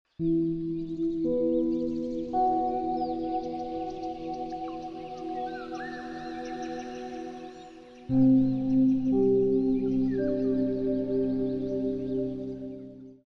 Relax with this calming waterfall scene and let nature wash your stress away 🌊🍃.